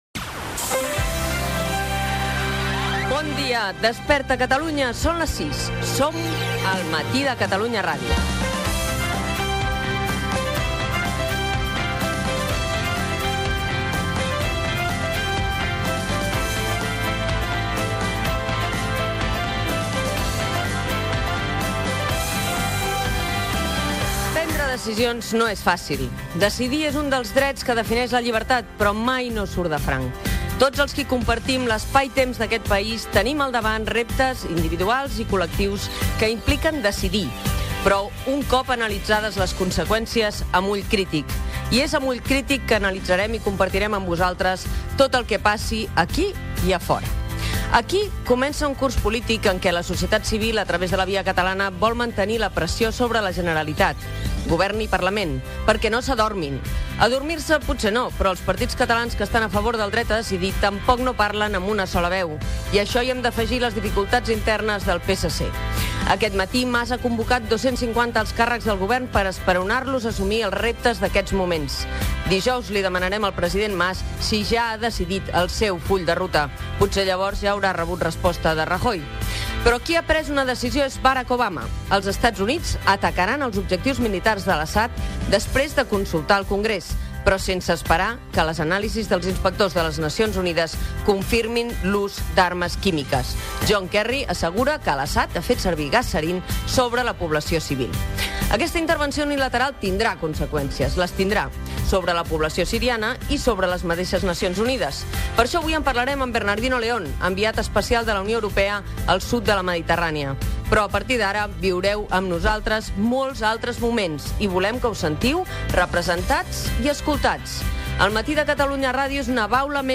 Sintonia, hora, identificació del programa, presentació amb els temes destacasts del dia, relacionats amb el fet de "decidir·.
Info-entreteniment
Primera edició del programa presentada per Mònica Terribas.